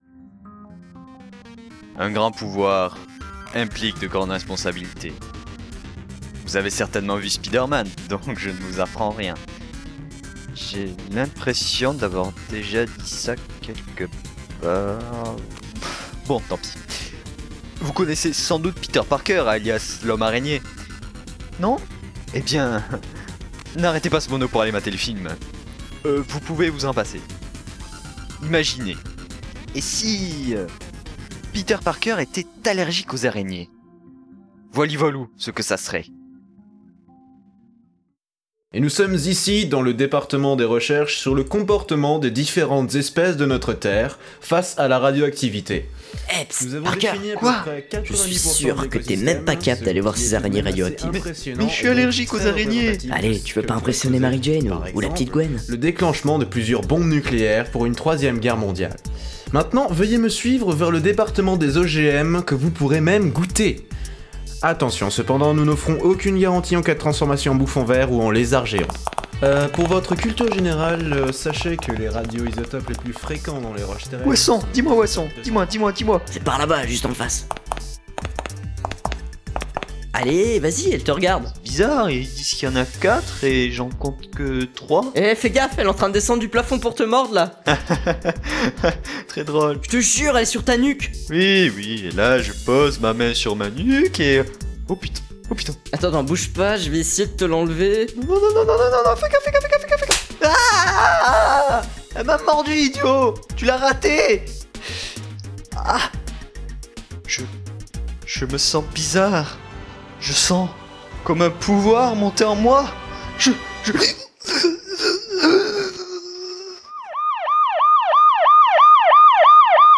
Distribution des rôles